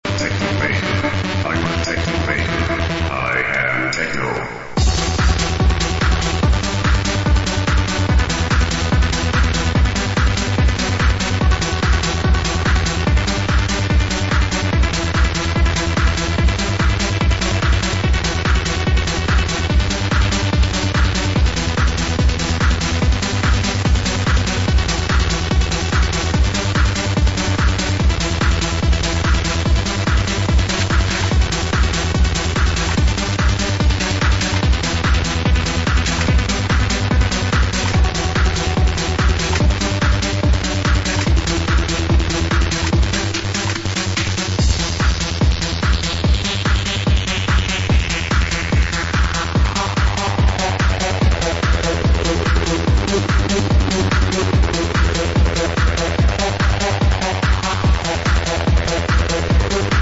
Catchy trance from 1999 onwards.